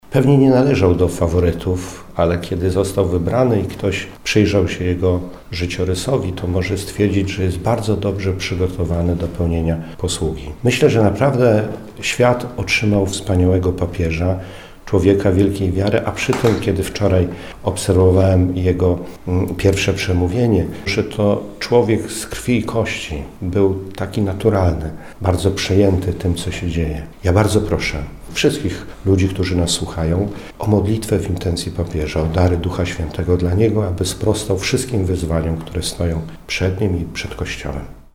Abp Wiesław Śmigiel, metropolita szczecińsko-kamieński: